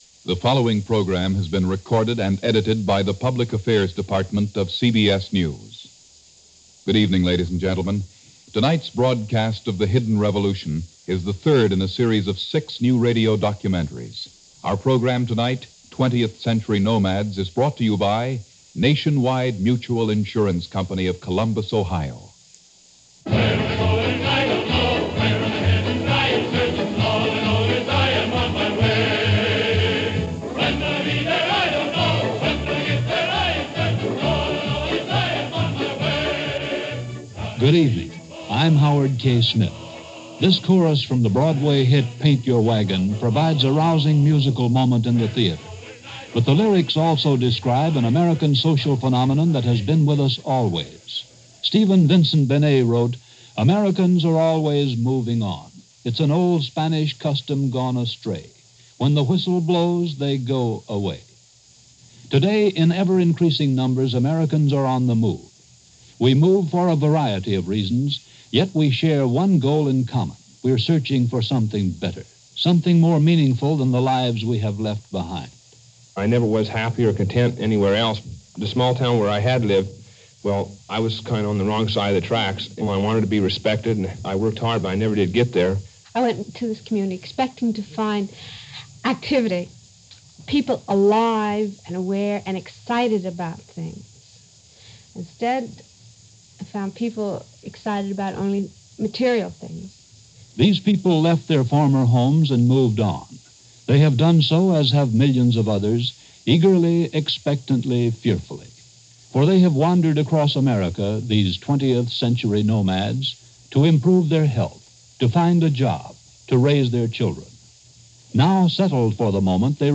The Great Radio Documentaries - 20th Century Nomads - Broadcast by CBS Radio On January 4, 1959 -Past Daily Pop Chronicles.